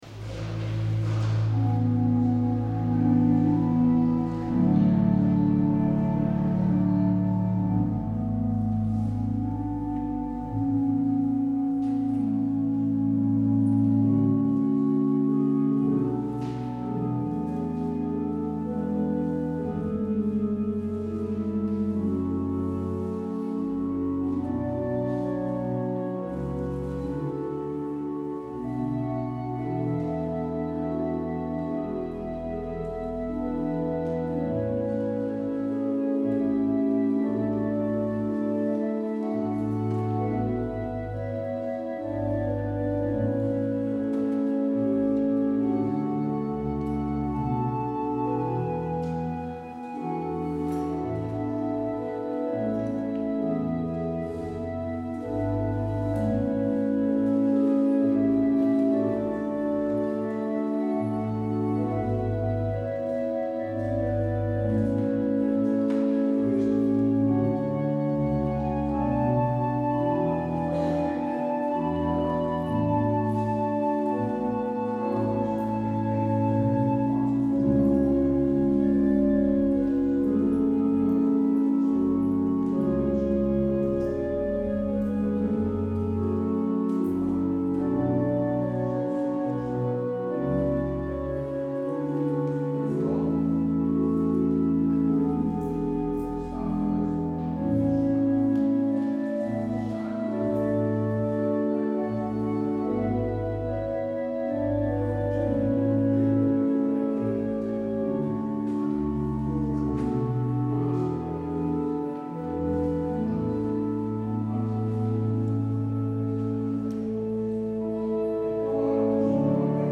 Luister deze kerkdienst hier terug: Alle-Dag-Kerk 9 mei 2023 Alle-Dag-Kerk https
Het openingslied is Lied 730: vers 1 en 3. Als slotlied hoort u Lied 756: de verzen 1, 2, 4, 5 en 6.